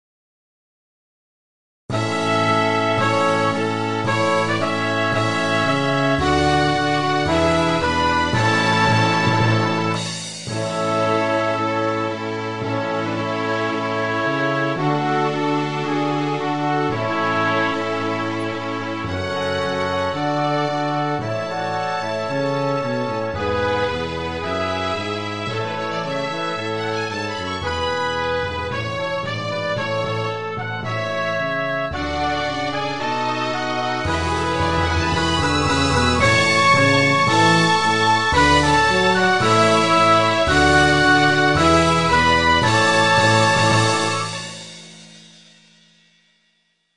교가